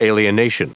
Prononciation du mot alienation en anglais (fichier audio)
Prononciation du mot : alienation